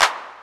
• Clap Single Hit C Key 34.wav
Royality free hand clap - kick tuned to the C note. Loudest frequency: 2353Hz
clap-single-hit-c-key-34-Yo6.wav